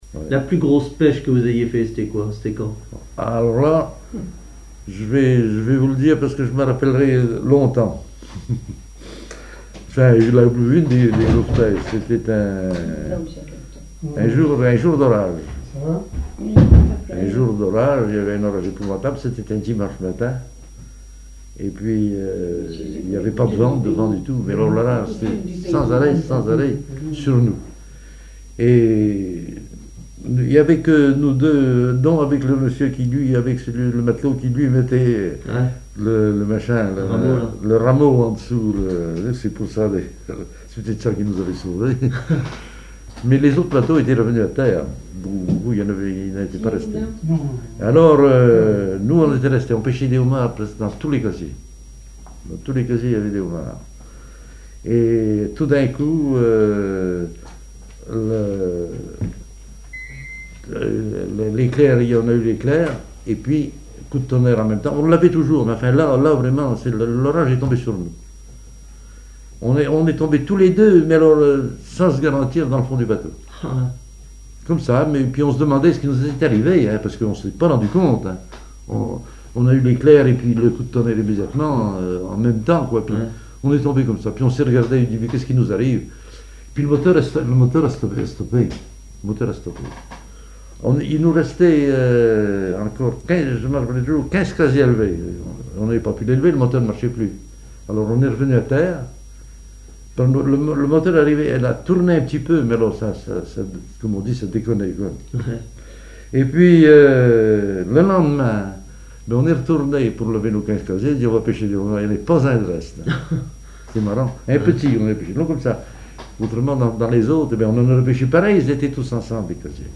témoignages sur les activités maritimes locales
Catégorie Témoignage